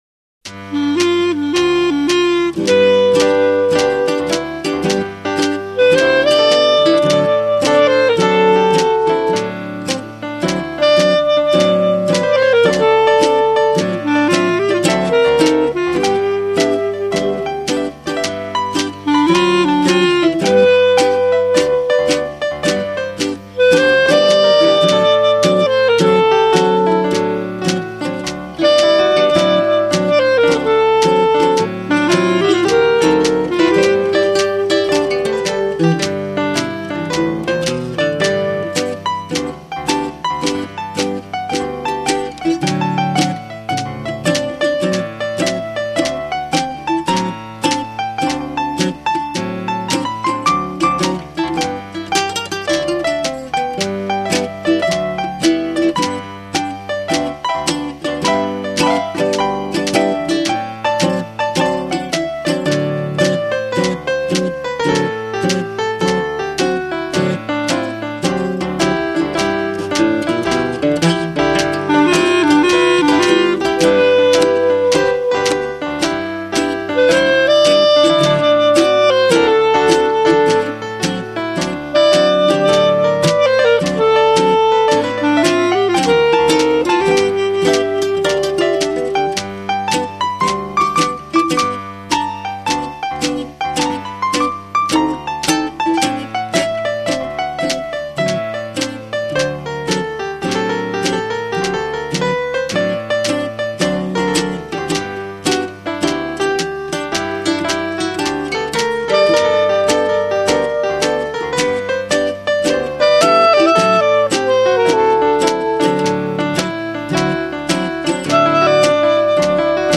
Clarinet, piano and mini-guitar